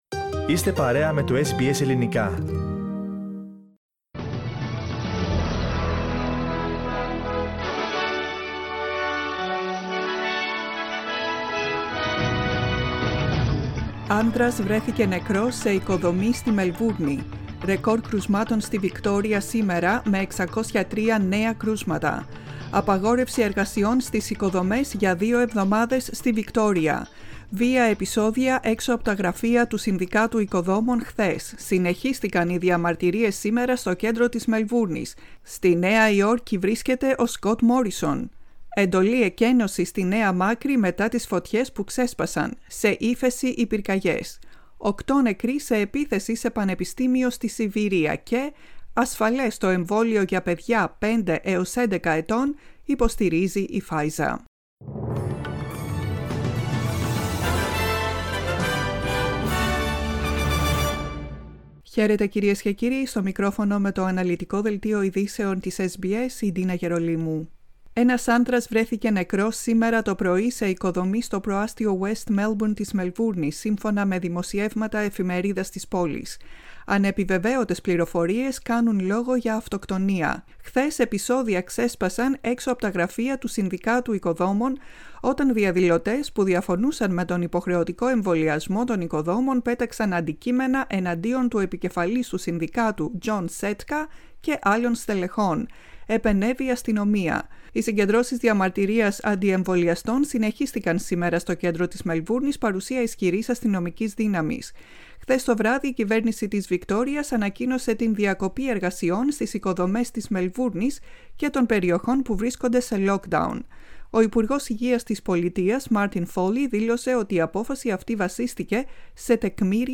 Δελτίο Ειδήσεων στα ελληνικά, 21.09.21
To κεντρικό δελτίο ειδήσεων του Ελληνικού Προγράμματος τη Τρίτη 21 Σεπτεμβρίου 2021.